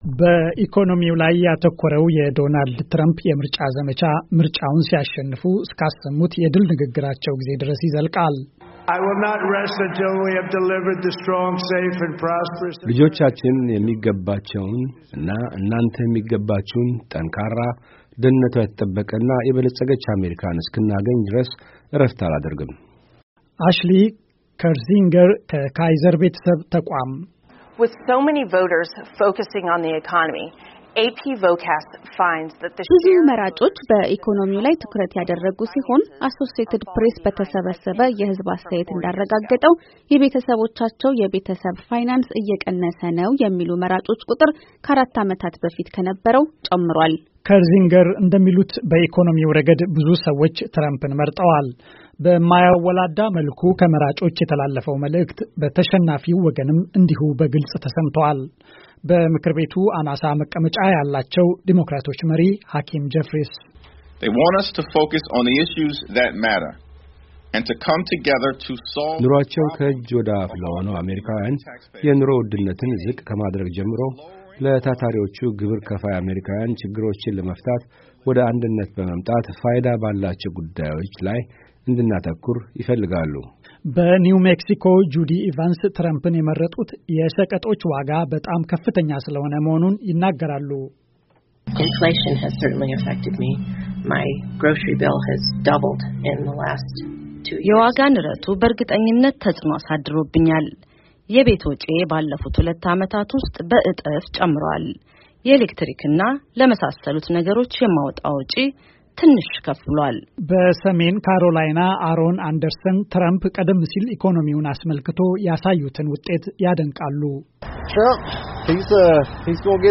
ዘገባ ከተያያዘው ፋይል ይከታተሉ።